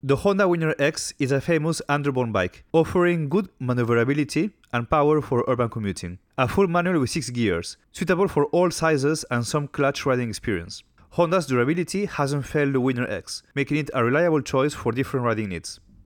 A great evolution from its predecessor the now legendary Winner, the Winner X gained in personality with the look and sound of a motorbike rather than a scooter.
Engine Size150 cc